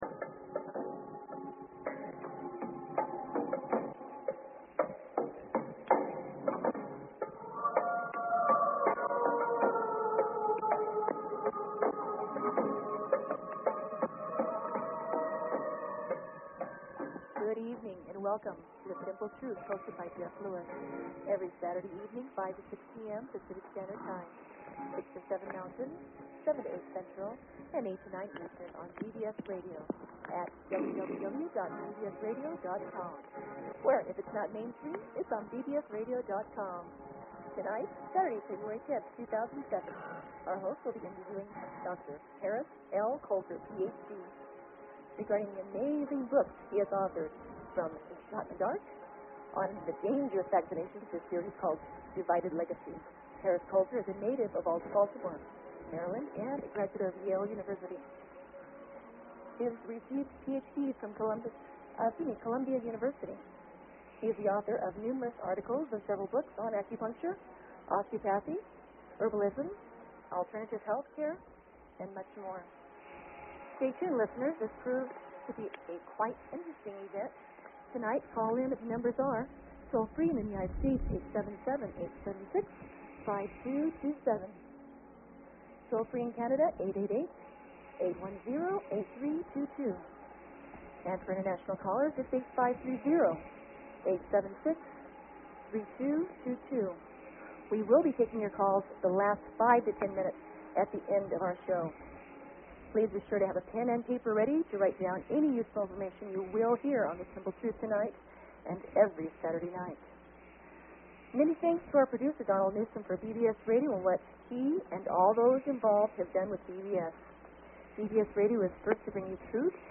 The Simple Truth will be focused around cutting-edge nutrition, health and healing products and modalities from around the world. We will be interviewing the worlds greatest health and nutrition experts regarding the latest research and developments in health and nutritional technology.